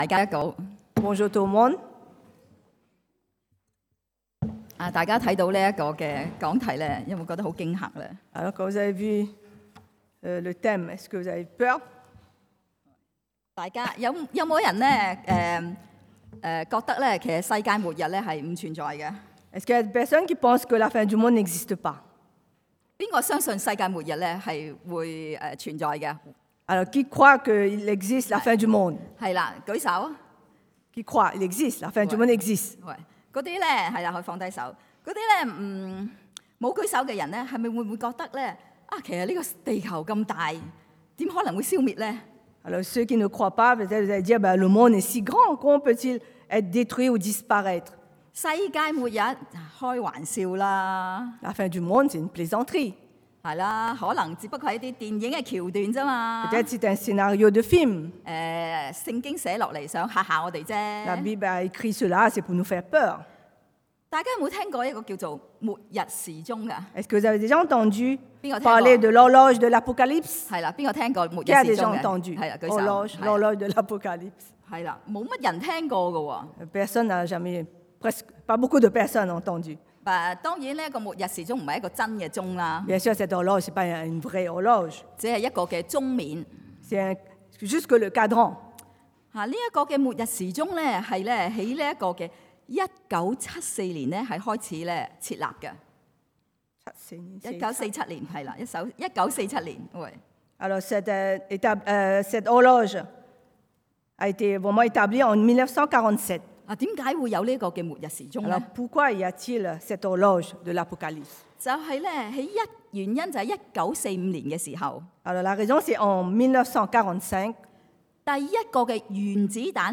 Fin du monde 世界末日 – Culte du dimanche
Predication du dimanche